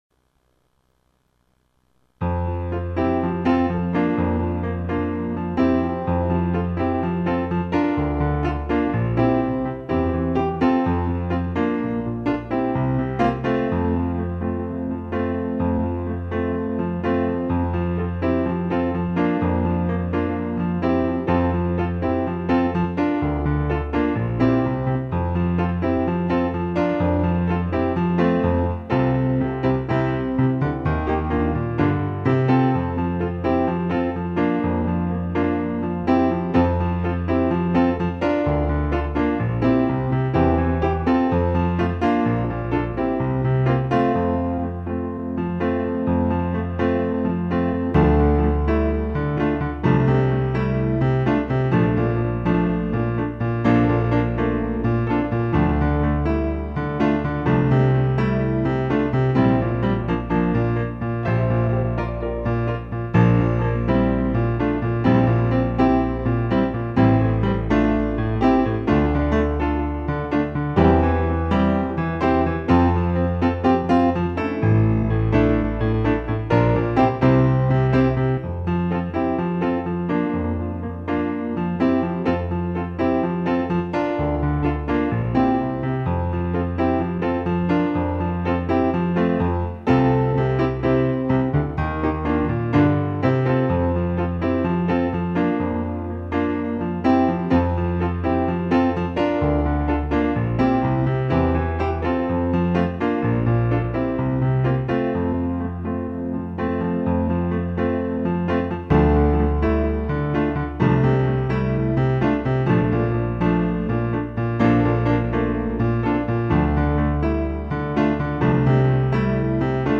Index of /arawangresources/mp3s no vocal
worship the king F 126 kb audio.mp3